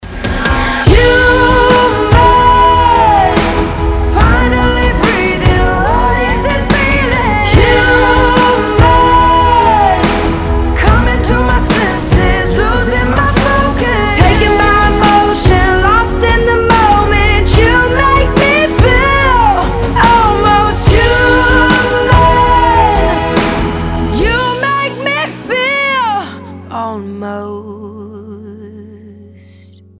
• Качество: 320, Stereo
поп
громкие
женский вокал
христианская музыка